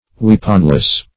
Meaning of weaponless. weaponless synonyms, pronunciation, spelling and more from Free Dictionary.
Search Result for " weaponless" : Wordnet 3.0 ADJECTIVE (1) 1. without a weapon ; The Collaborative International Dictionary of English v.0.48: Weaponless \Weap"on*less\, a. Having no weapon.